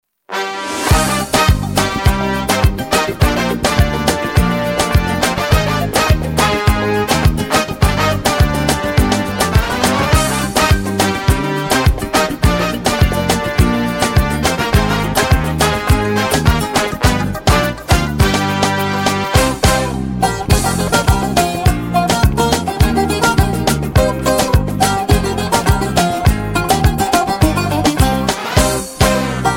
ویژگی‌های این موزیک بی‌کلام:
🎵 کیفیت صدای عالی و استودیویی
🎧 بدون افت کیفیت و نویز
پاپ